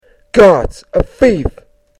Englische Sprecher (m)
Thief.mp3